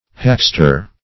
Search Result for " hackster" : The Collaborative International Dictionary of English v.0.48: Hackster \Hack"ster\ (-st[~e]r), n. [From Hack to cut.] A bully; a bravo; a ruffian; an assassin.